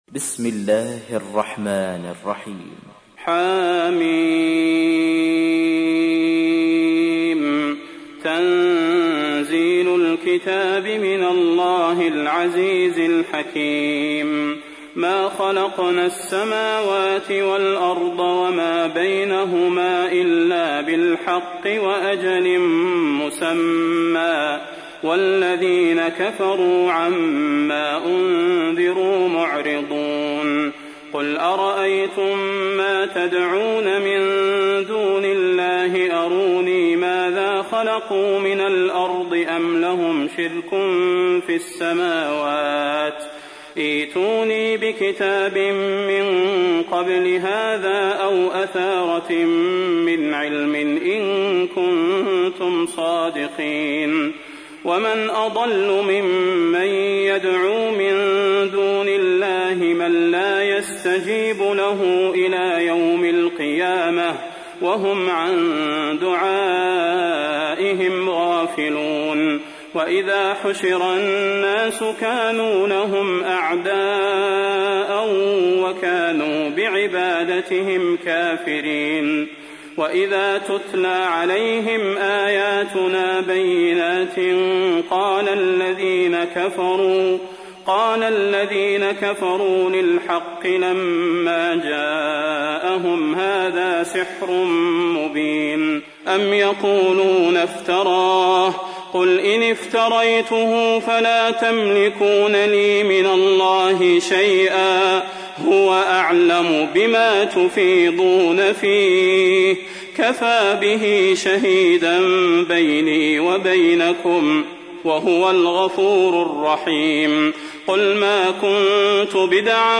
تحميل : 46. سورة الأحقاف / القارئ صلاح البدير / القرآن الكريم / موقع يا حسين